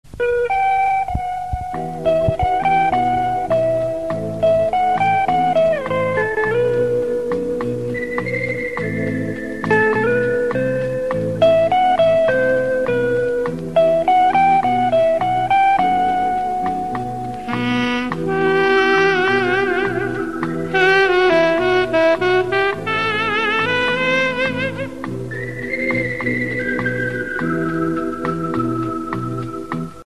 Гитара и саксофон.